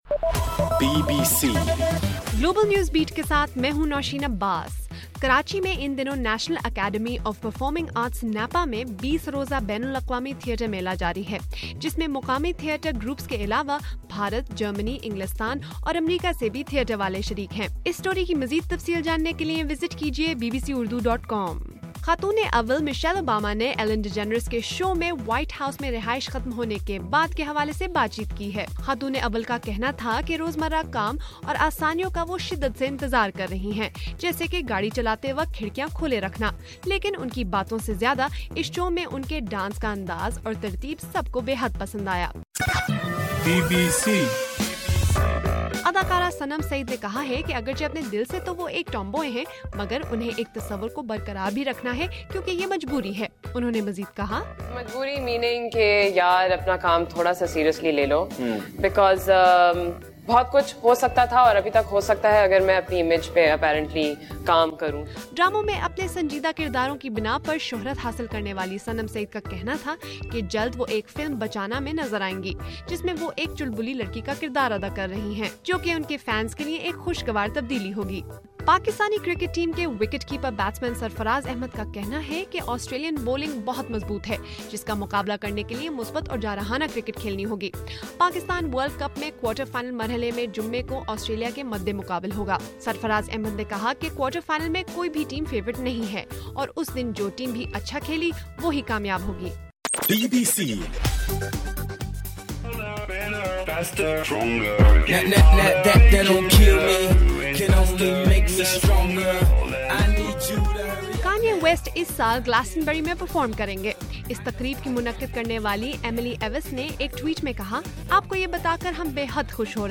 مارچ 18: صبح 1 بجے کا گلوبل نیوز بیٹ بُلیٹن